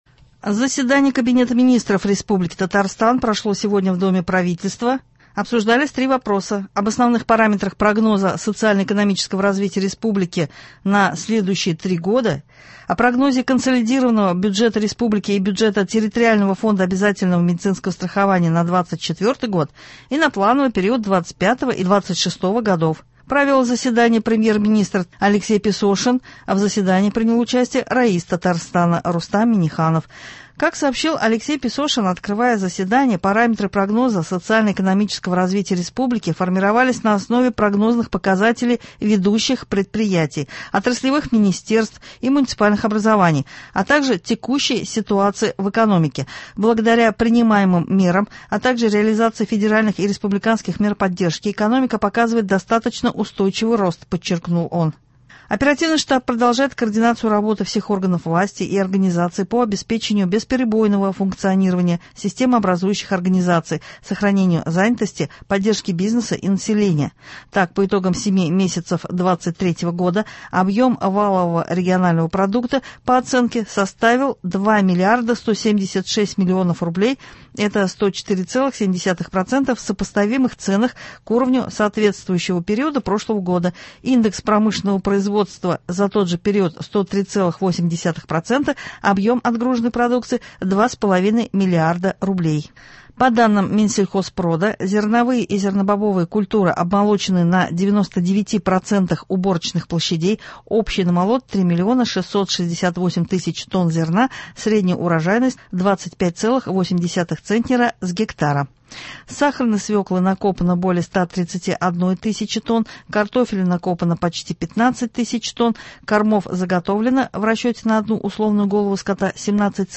Утренний выпуск.
Новости (06.09.23)